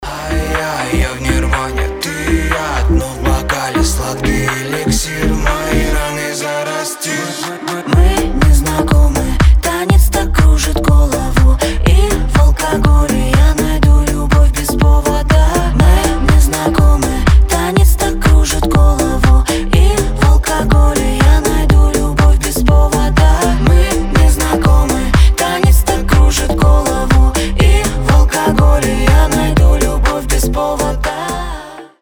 • Качество: 320, Stereo
ритмичные
dance
club